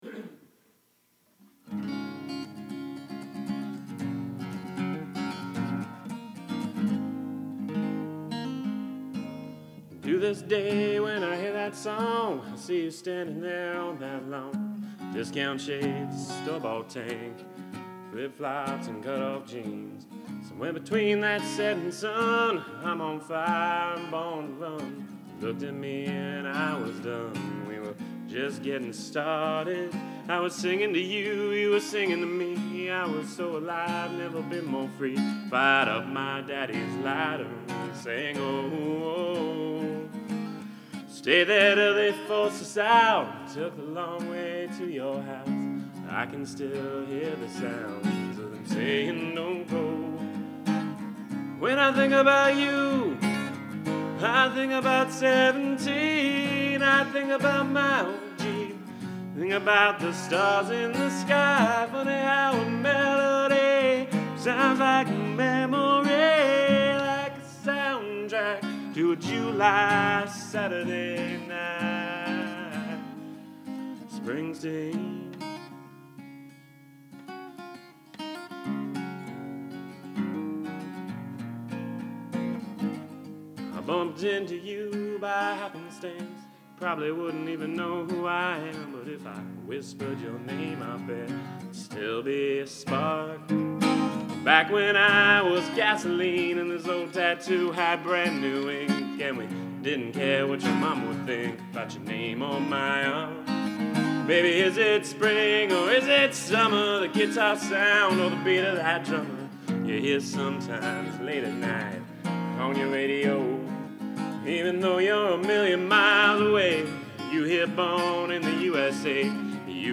(acoustic)